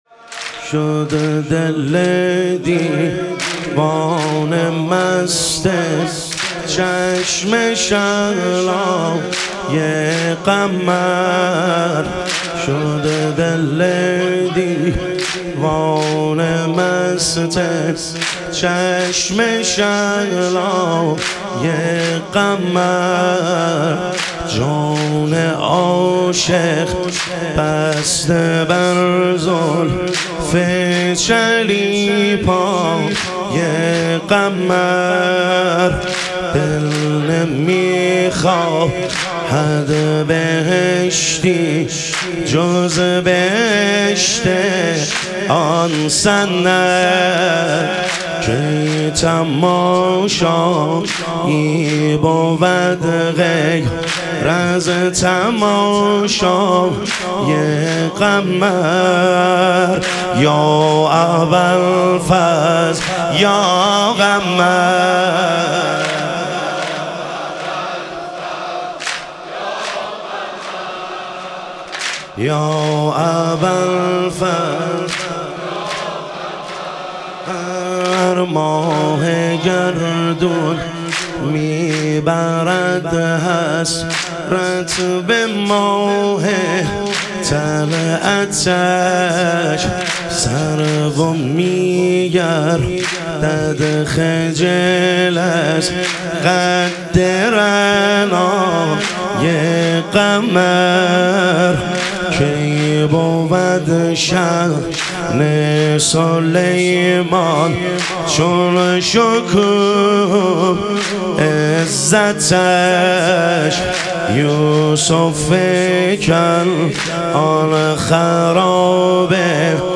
شهادت حضرت ام البنین علیها سلام - واحد